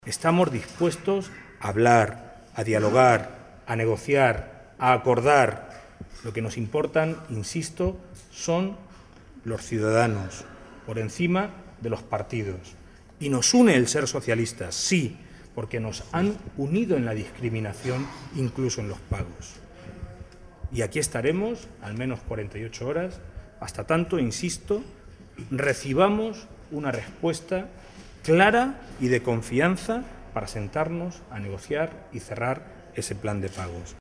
Audio alcalde Puertollano-2